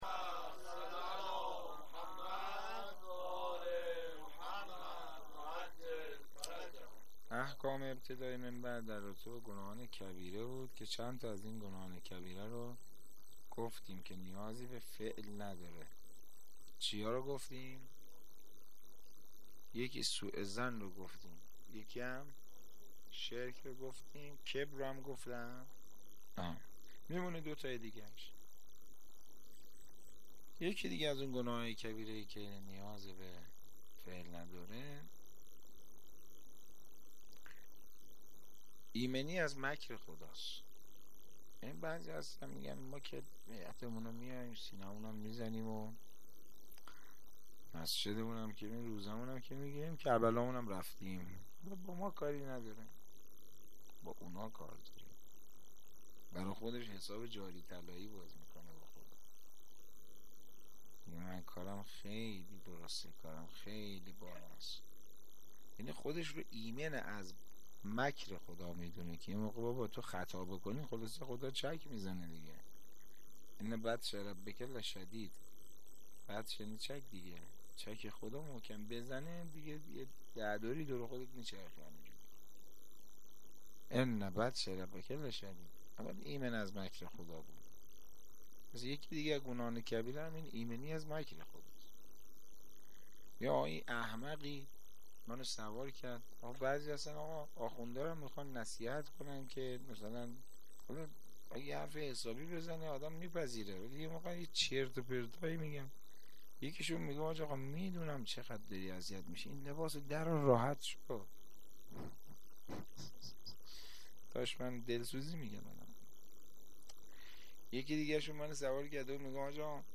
Sokhanrani-3.mp3